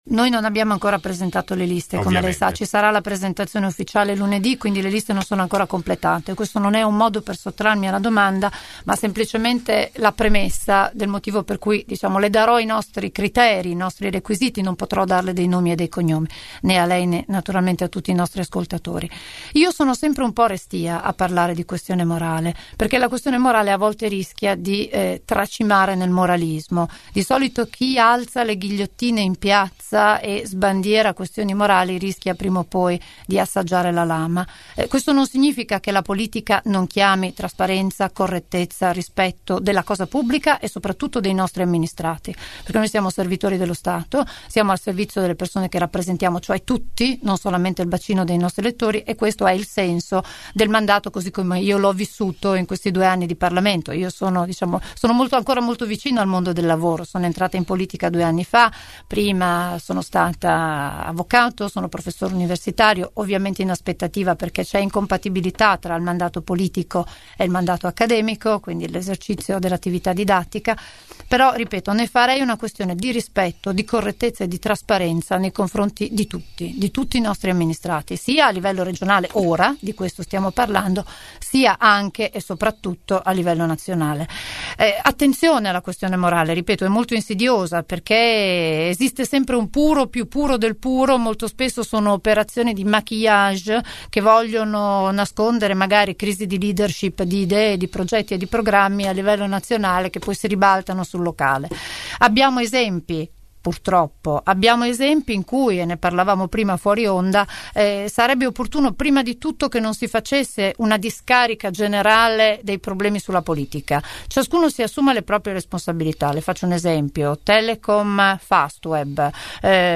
E’ iniziato oggi, con Anna Maria Bernini del Pdl Lega Nord, il primo dei quattro incontri con i candidati alla presidenza della Regione Emilia Romanga. Ecco una sintesi dell’intervista andata in onda all’interno di Angolo B.